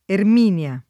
Erminio [ erm & n L o ]